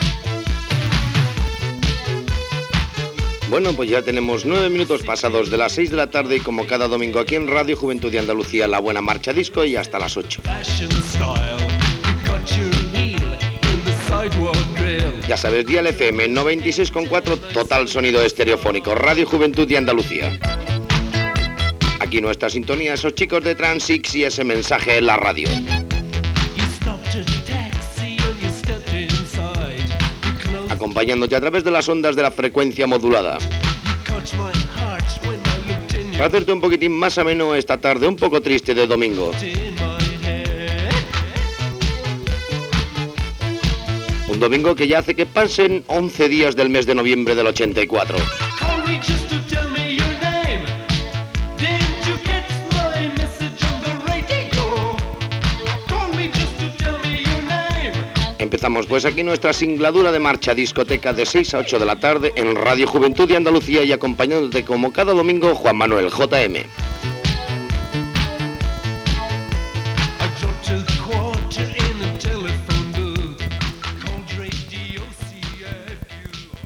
Presentació de l'inici del programa.
Musical